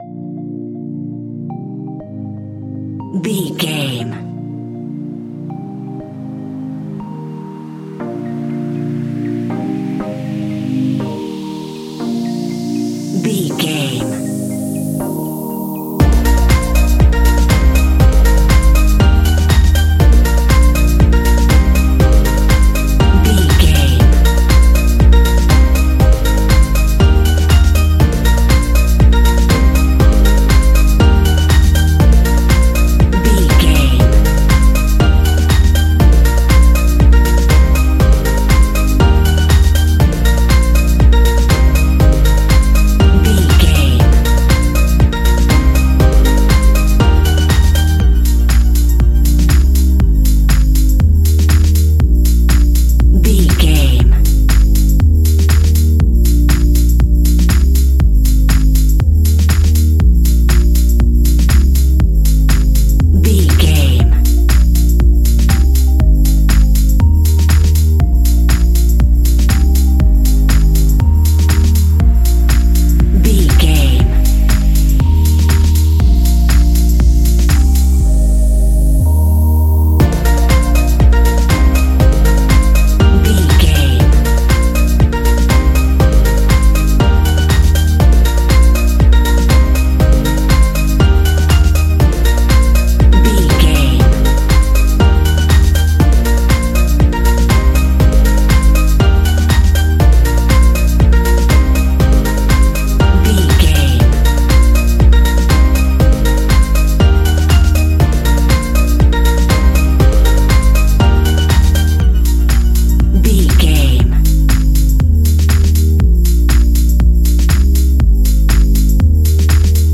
Ionian/Major
D♯
house
electro dance
synths
techno
trance
instrumentals